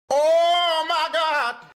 Oh My God Meme Sound sound effects free download